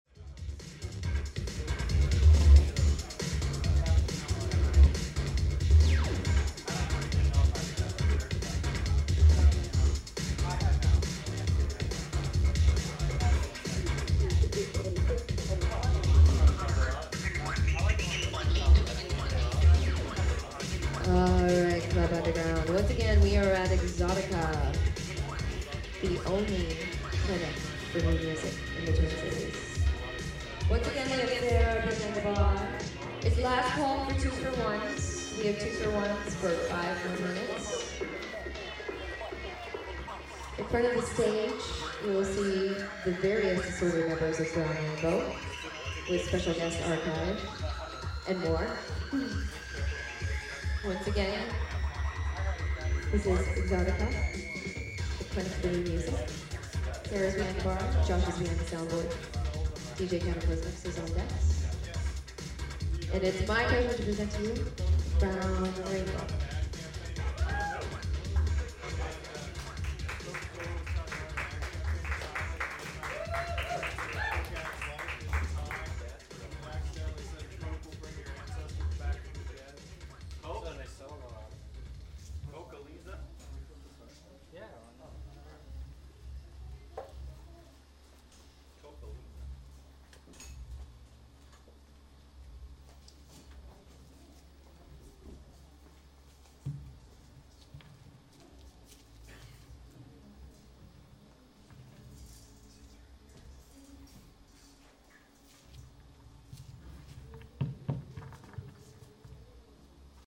Location: Club Underground